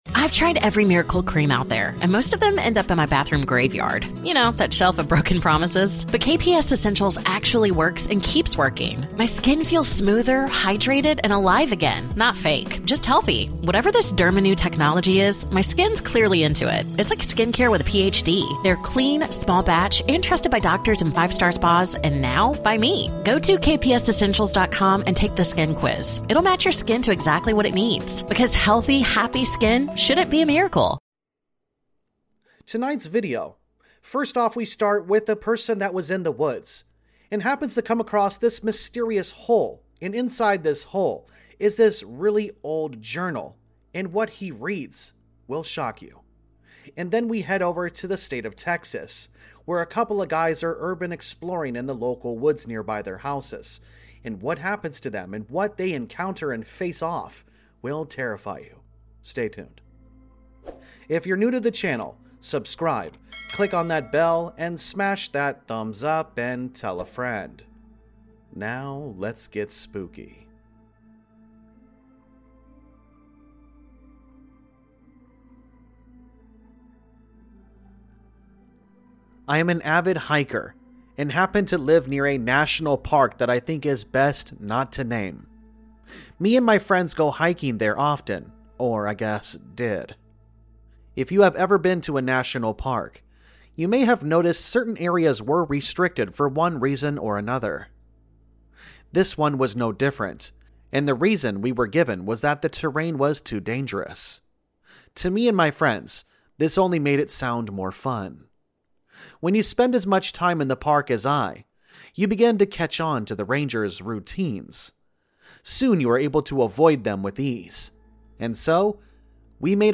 All Stories are read with full permission from the authors